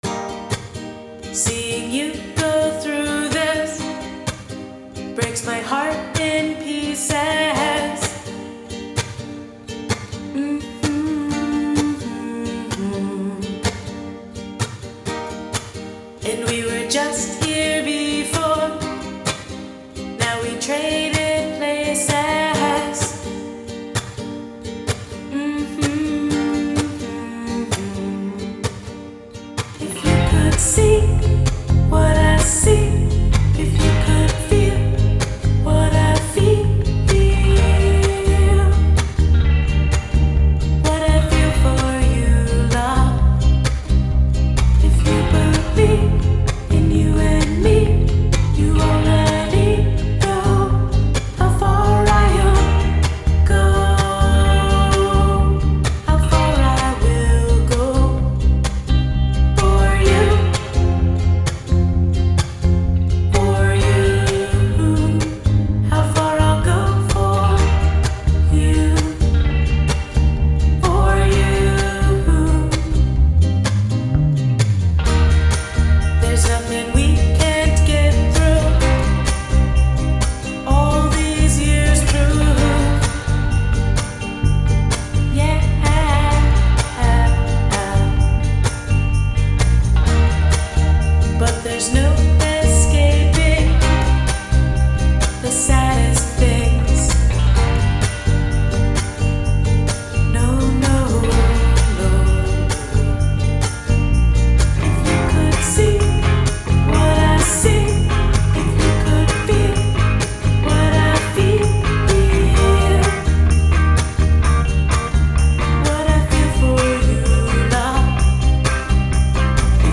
Genre: singersongwriter.